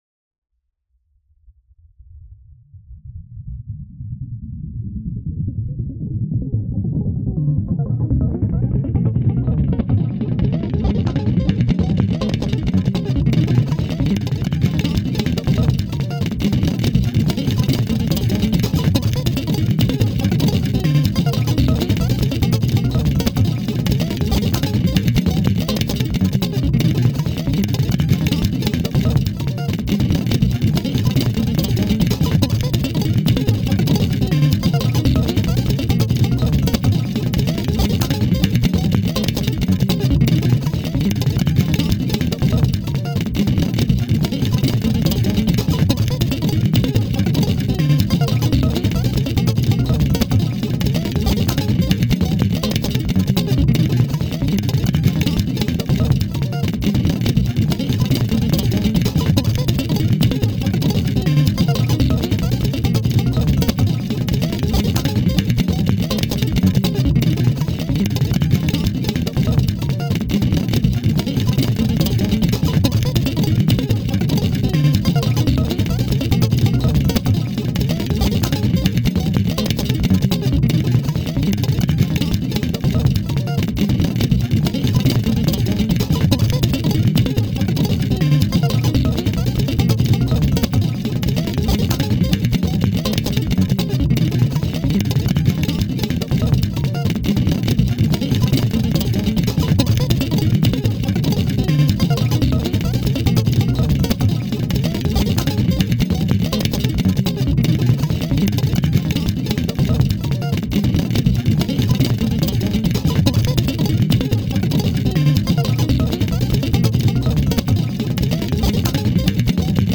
呪術的、儀式的な、プレクティクス・パルス・ミュージック。
パルスによる超感覚的な酩酊感。
パルスにおけるプレクティクス・ギター・オーケストレーション。